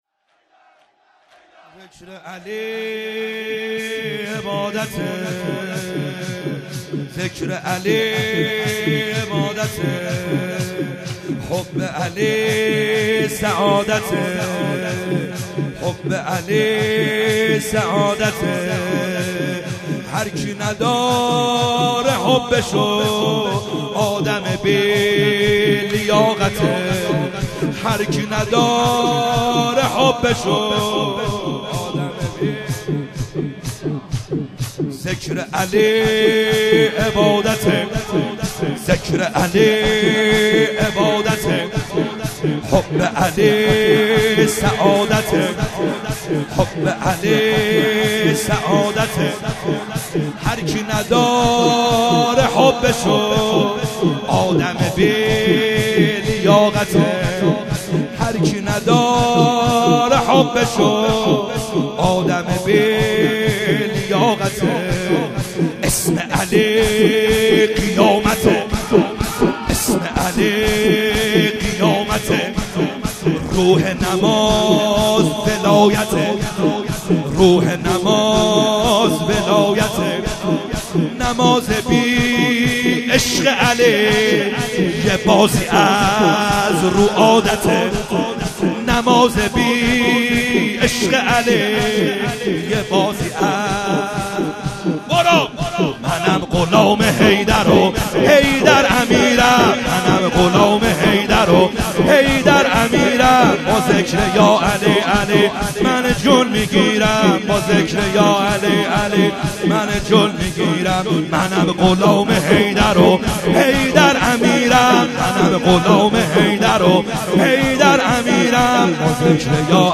خیمه گاه - بیرق معظم محبین حضرت صاحب الزمان(عج) - شور | ذکر علی عبادته